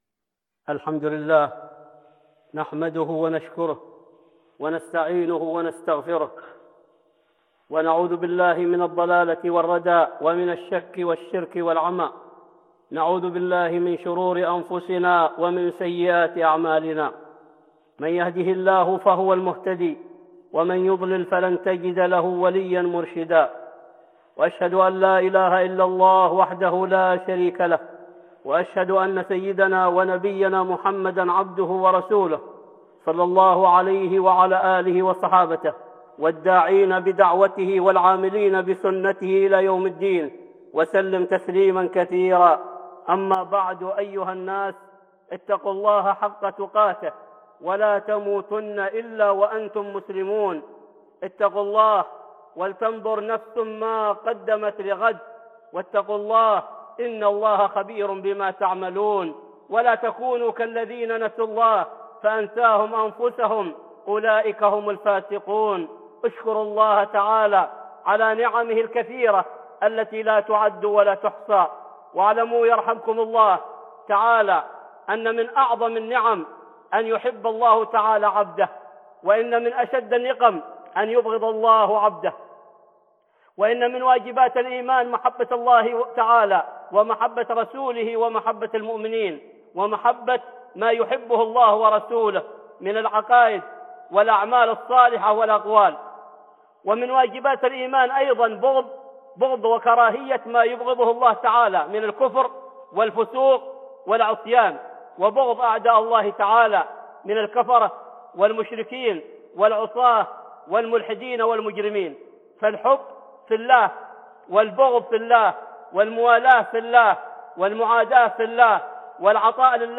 (خطبة جمعة) محبه الله ورسوله 1
خطبة جمعة بعنوان محبه الله ورسوله 1